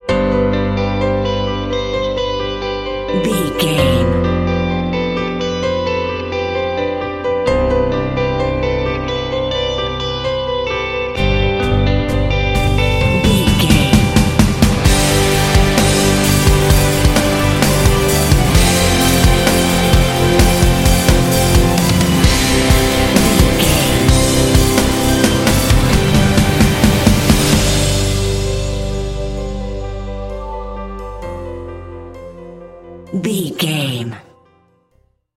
Epic / Action
Mixolydian
intense
proud
inspirational
piano
electric guitar
drums
strings
synthesiser
cinematic
symphonic rock